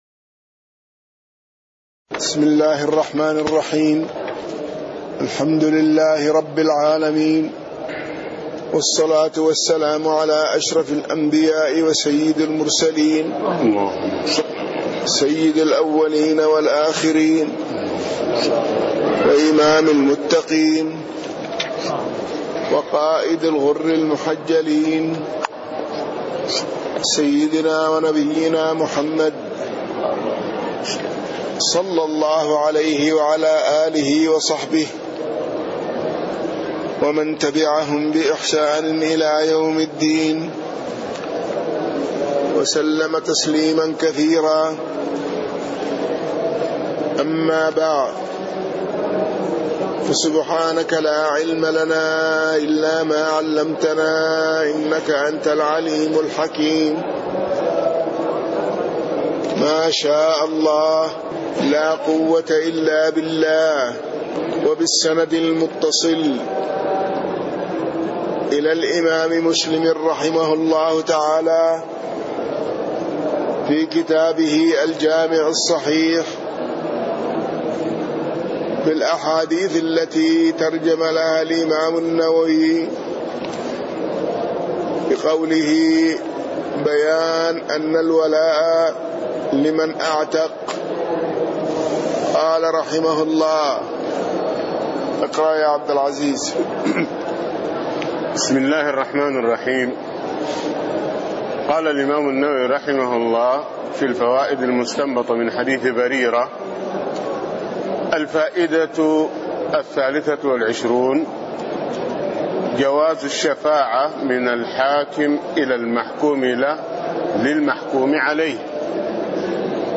تاريخ النشر ١٨ شوال ١٤٣٤ هـ المكان: المسجد النبوي الشيخ